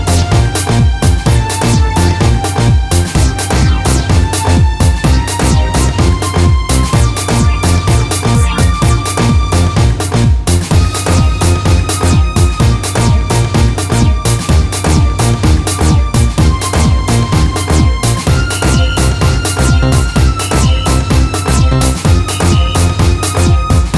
no Backing Vocals Crooners 4:21 Buy £1.50